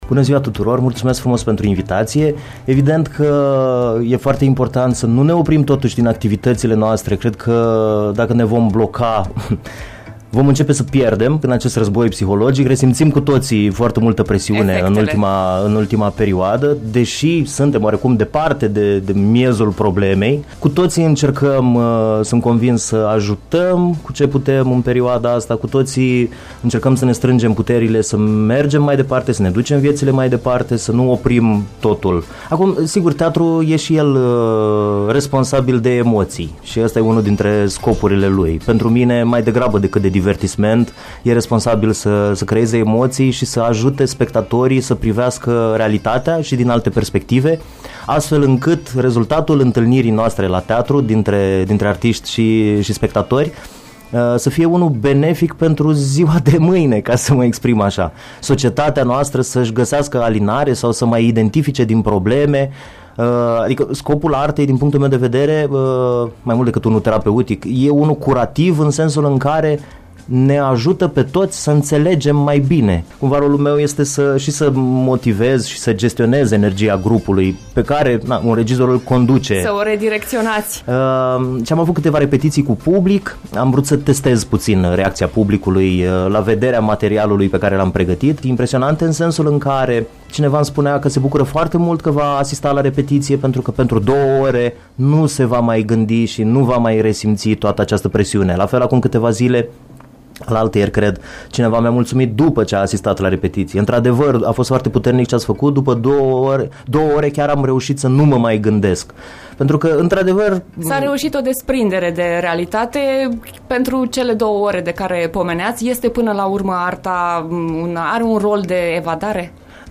O discuție reconfortantă despre puterea magică a teatrului.